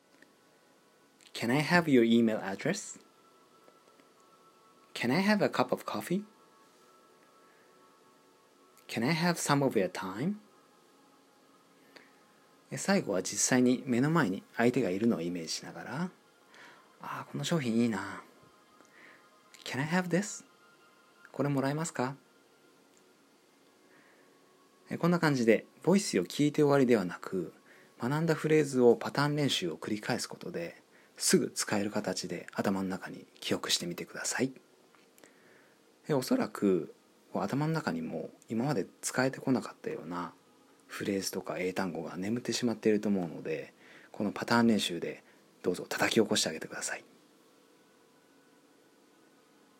フレーズなどのパターンの一部を変える反復練習のことだ。